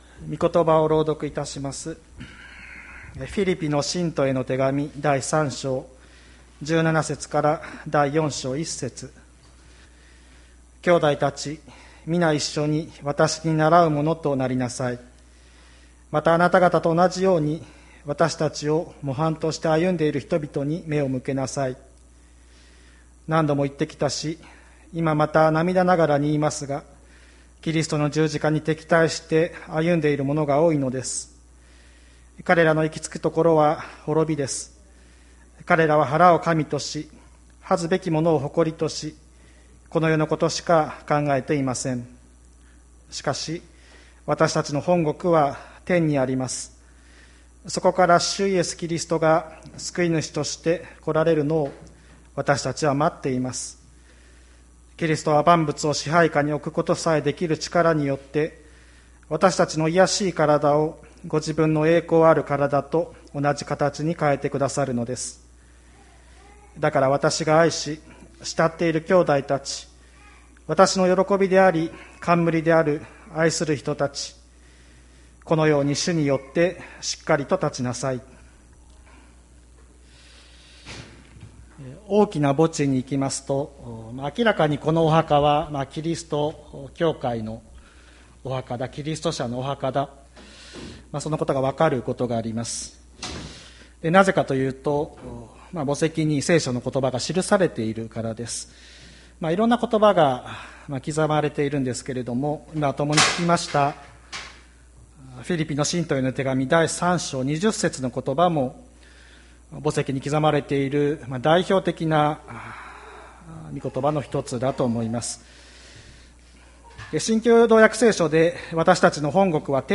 2021年07月04日朝の礼拝「我らの国籍は天にあり」吹田市千里山のキリスト教会
千里山教会 2021年07月04日の礼拝メッセージ。